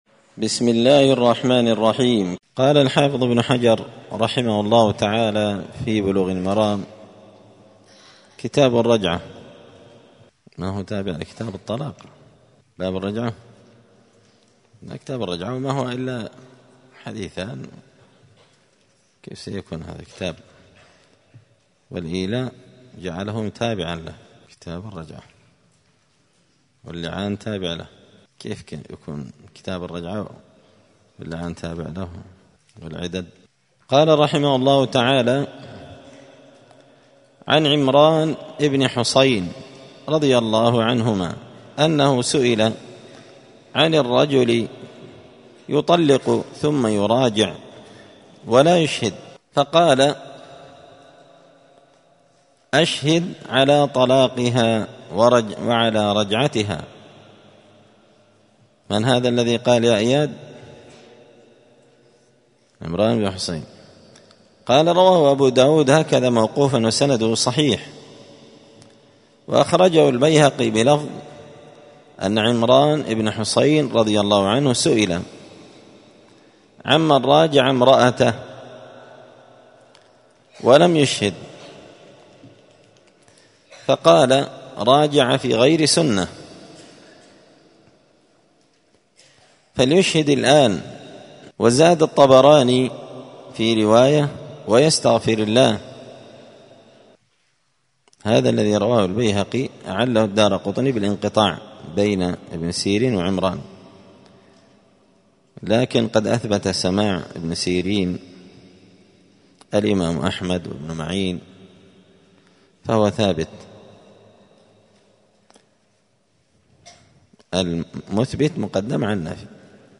*الدرس التاسع (9) {باب الرجعة}*